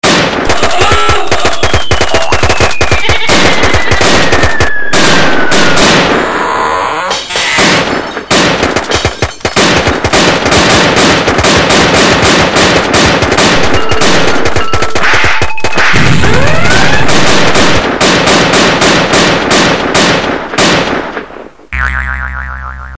Sound Design
HEAR the basement explode!